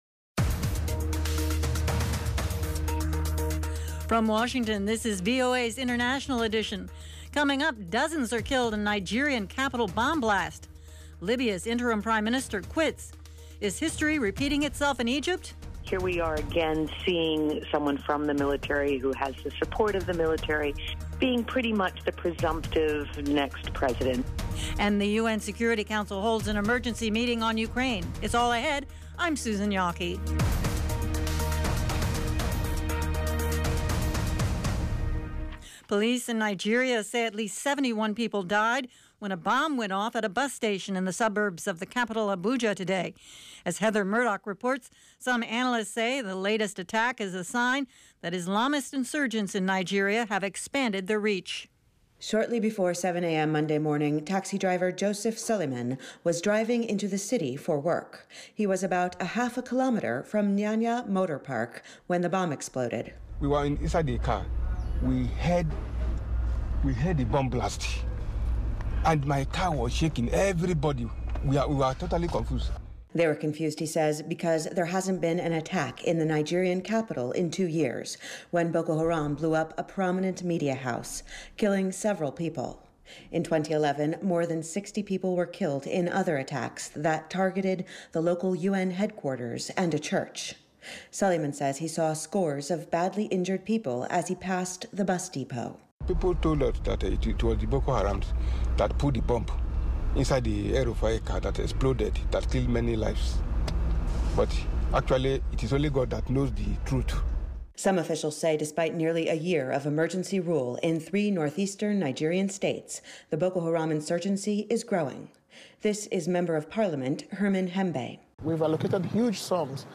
International Edition gives you 30 minutes of in-depth world news reported by VOA’s worldwide corps of correspondents - on the events people are talking about.